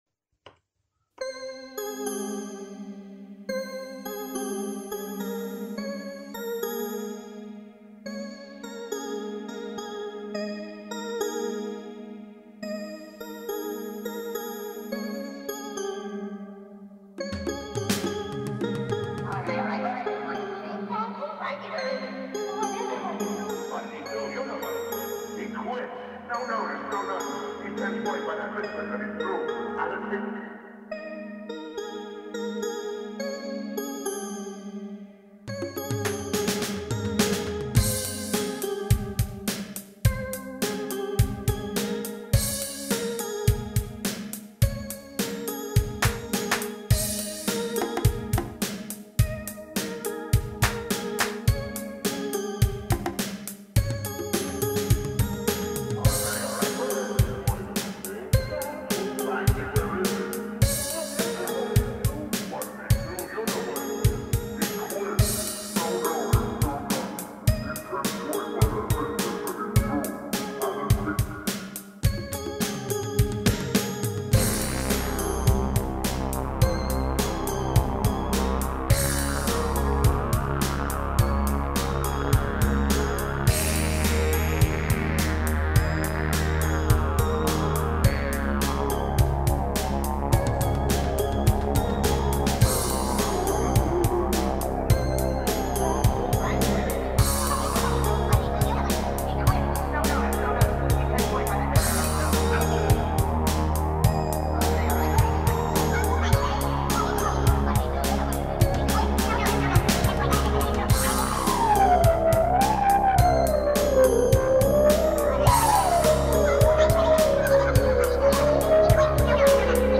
Live analog synth music from my standing rack, 2023-2034
Moody synthy sampledarkwave
• Bass - Wasp
• Drums - SR16
• Lead - ProVS